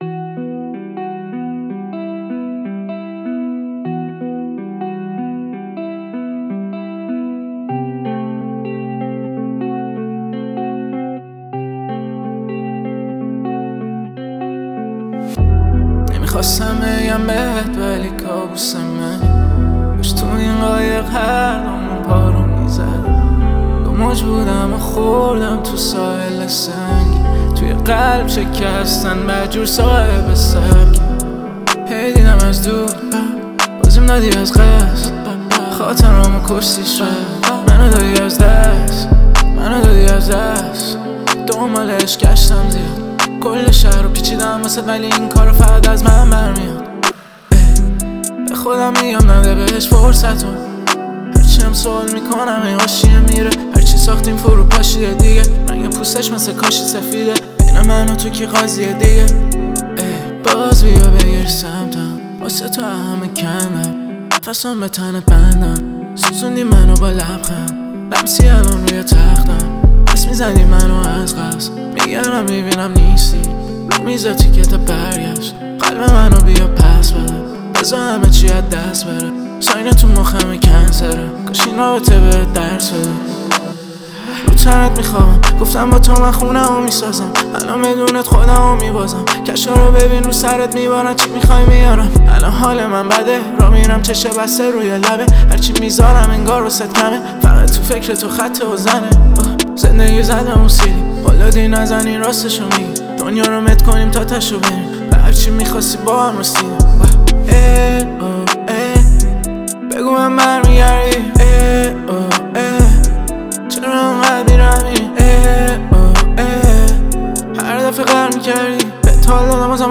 آهنگ عاشقانه رپ